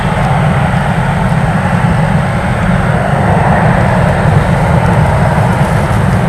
jet-blast.wav